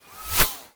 bullet_flyby_08.wav